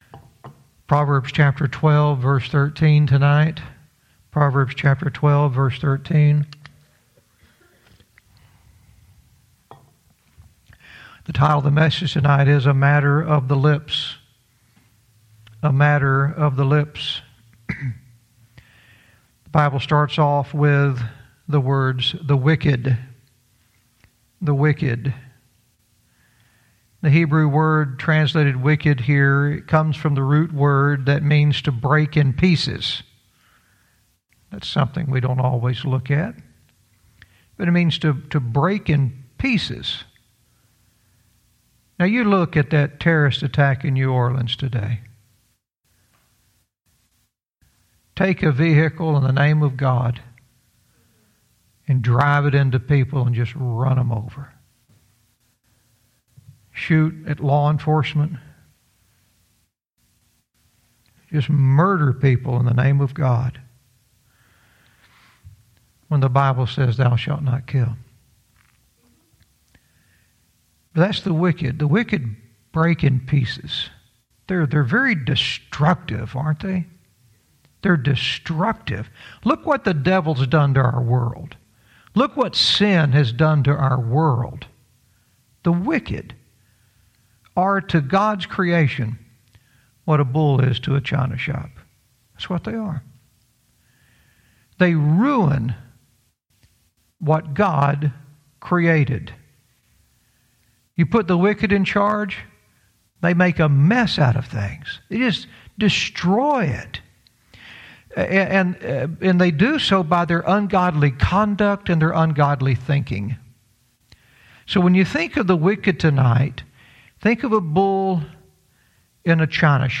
Verse by verse teaching - Proverbs 12:13 "A Matter of the Lips"